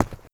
Footstep_HardSurface_03.wav